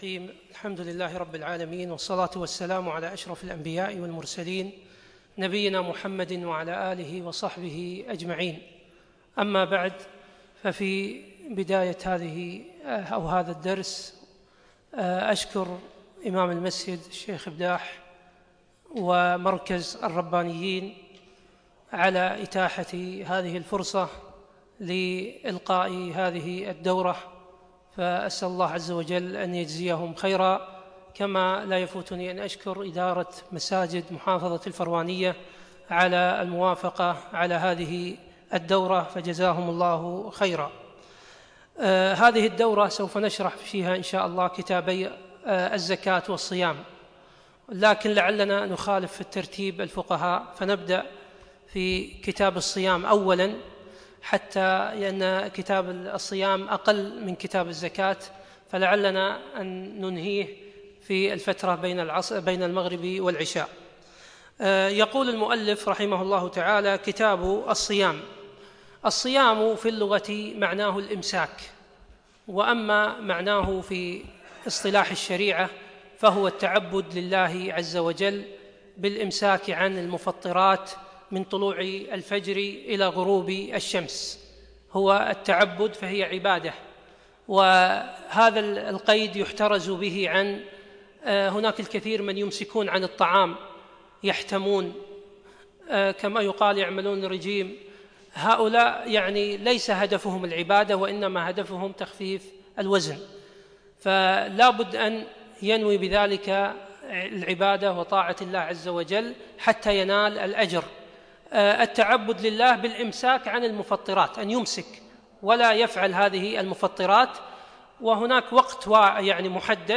يوم الاربعاء 25 شعبان 1437هـ الموافق 1 6 2016م في مسجد عايض المطيري الفردوس